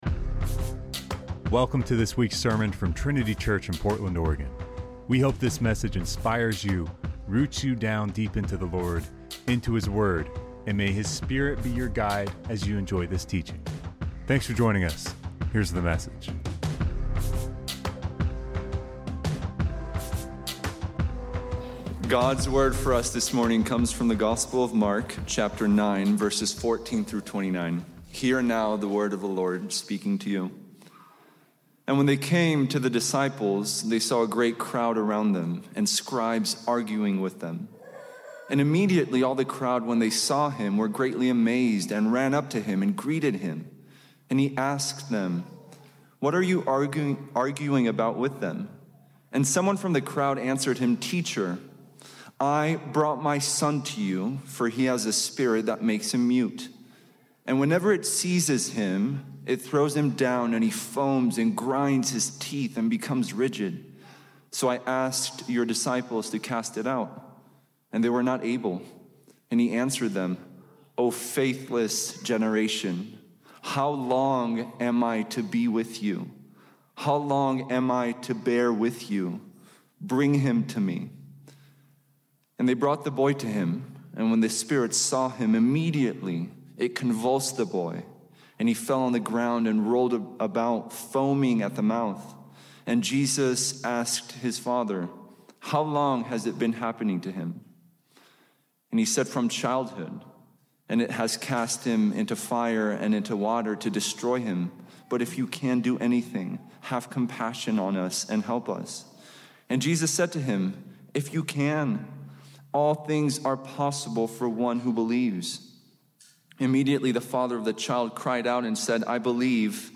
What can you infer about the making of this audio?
Welcome to this week’s sermon from Trinity Church in Portland, Oregon.